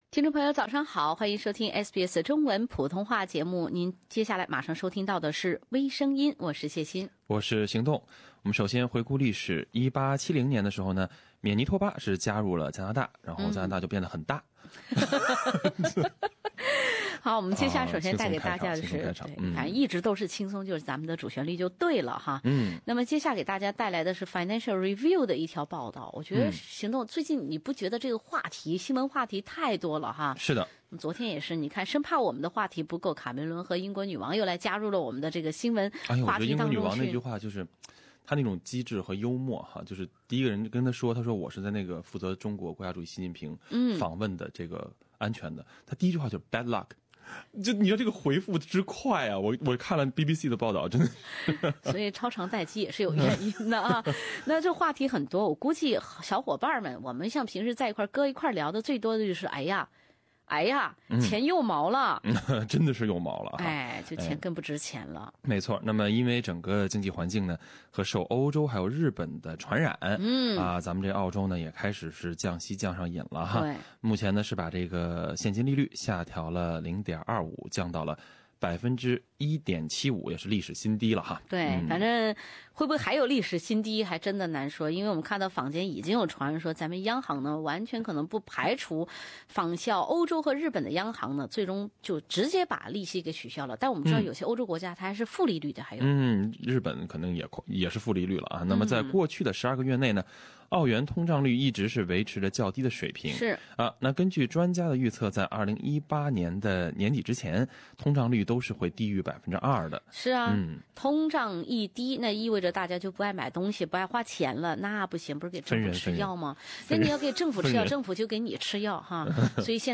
另類輕松的播報方式，深入淺出的辛辣點評；包羅萬象的最新資訊；傾聽全球微聲音。